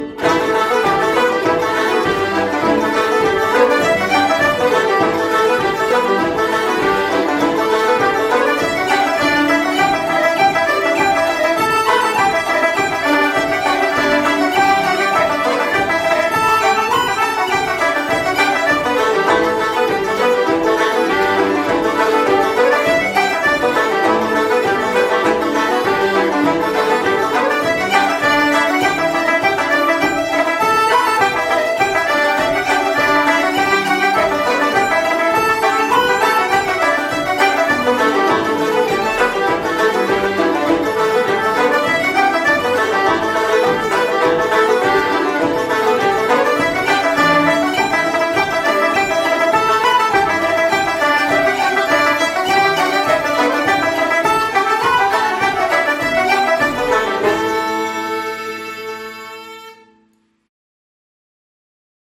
Sporting Paddy reel set